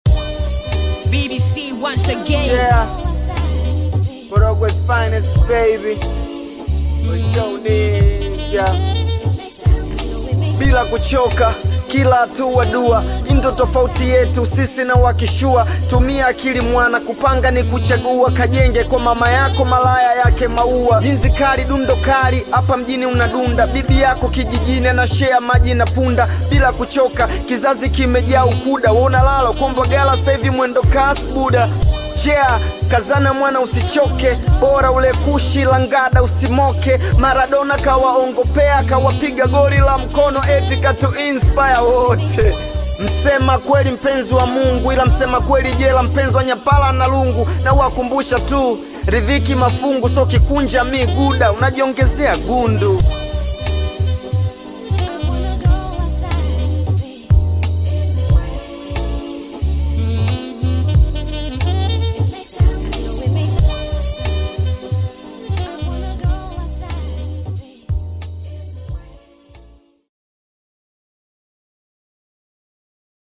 Bongo Flava music track
Tanzanian Bongo Flava artist, rapper, singer and songwriter